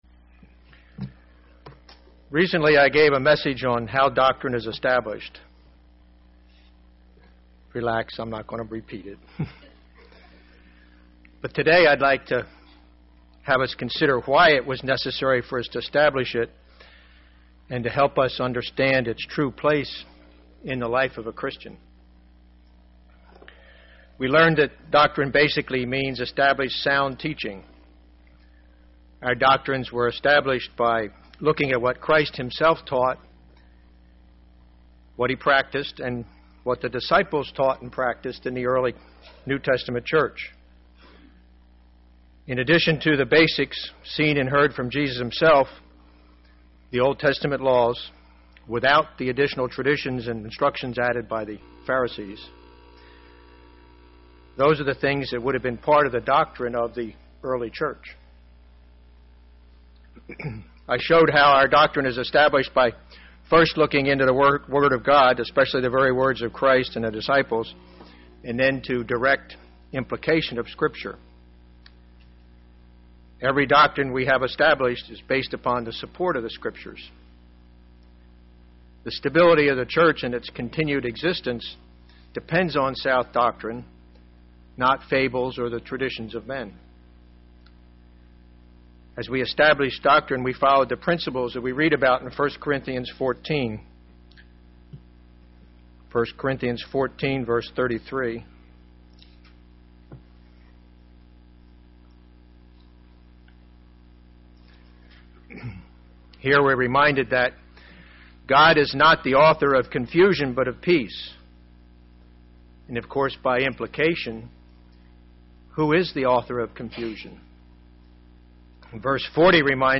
Given in Tampa, FL
UCG Sermon Studying the bible?